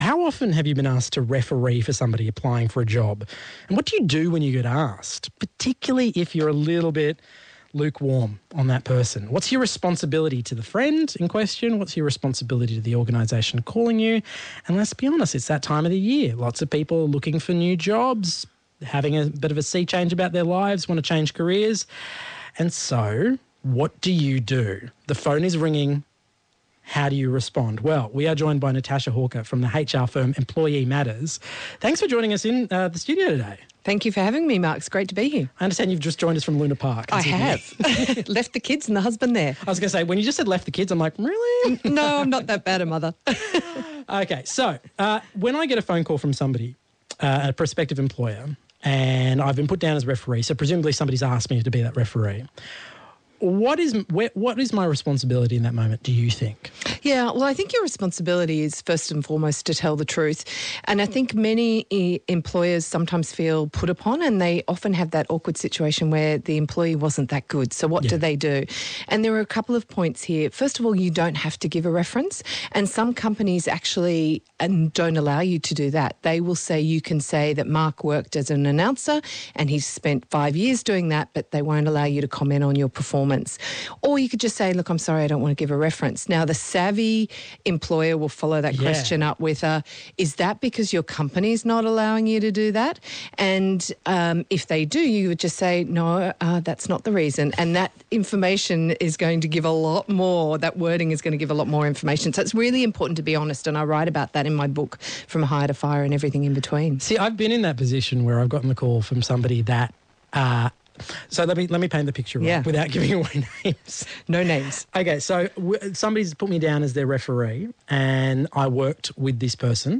referees-interview.mp3